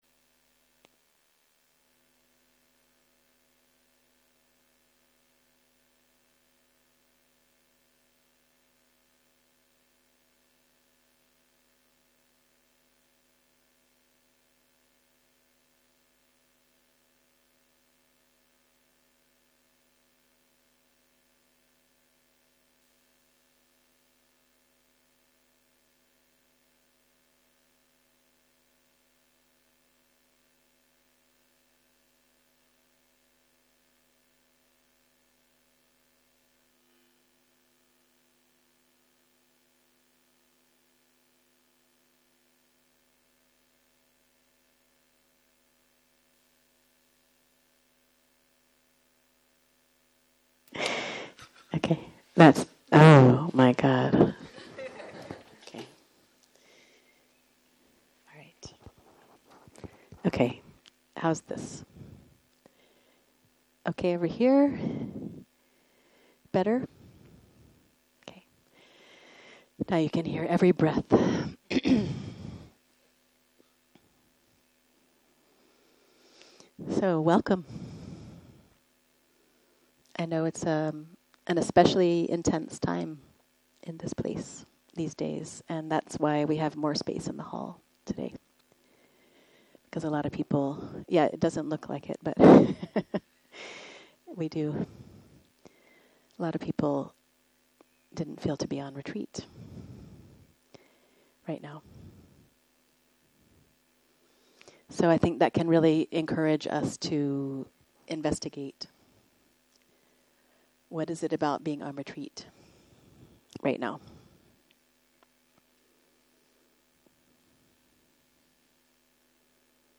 03.03.2023 - יום 1 - ערב - שיחת דהרמה - הקלטה 1
Dharma type: Dharma Talks שפת ההקלטה